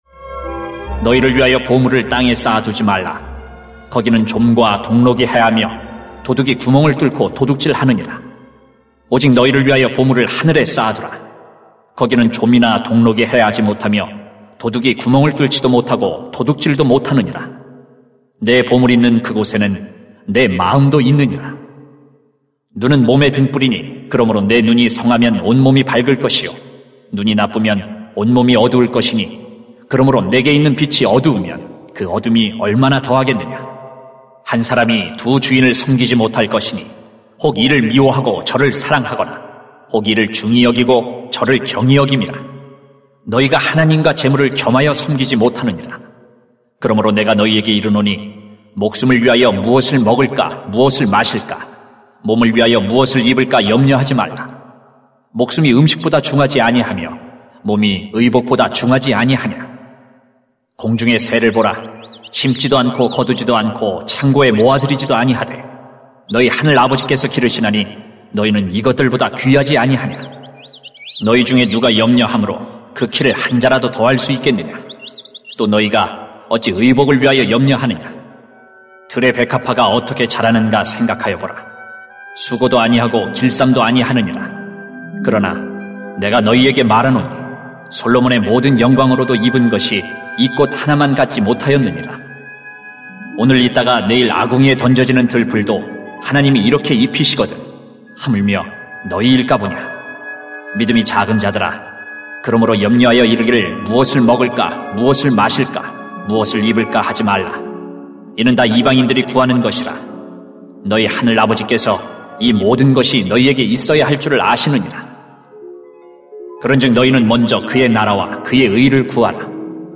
[마 6:19-34] 우리가 구해야 할 것이 있습니다 > 새벽기도회 | 전주제자교회